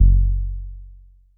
BASS1 F#1.wav